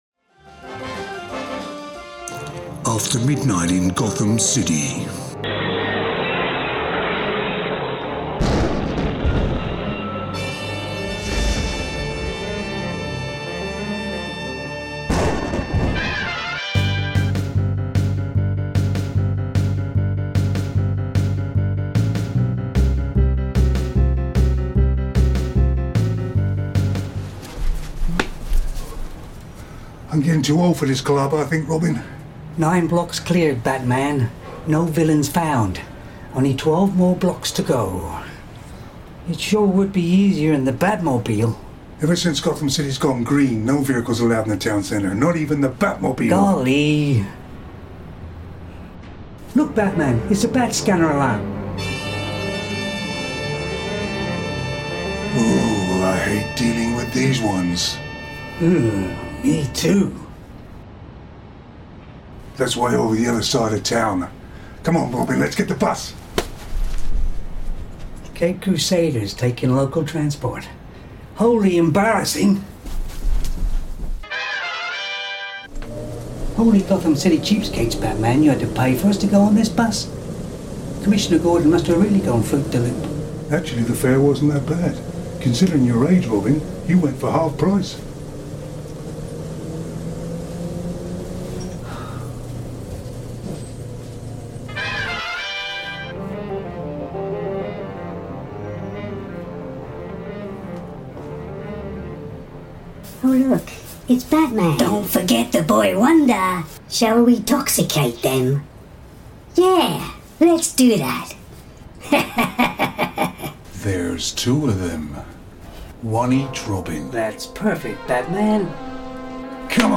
Thats why my voice was high....thanks for the tip buddy